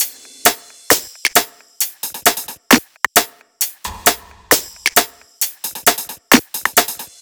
HP133BEAT3-L.wav